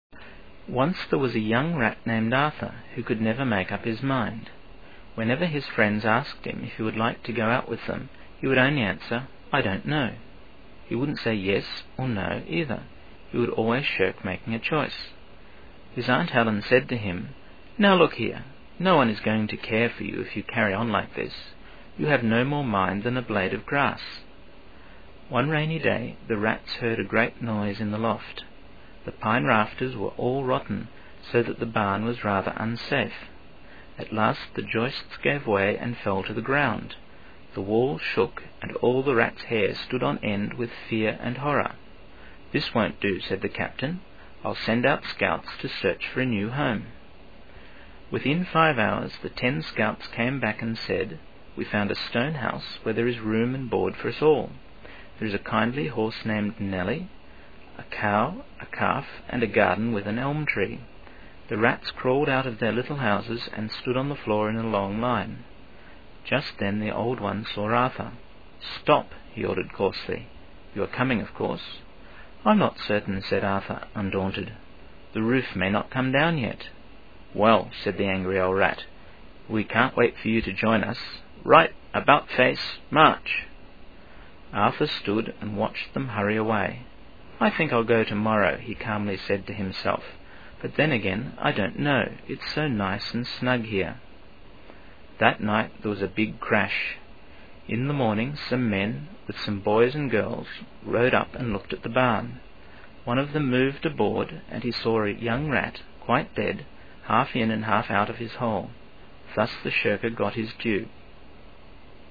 {GAus}
arth_Gaus.mp3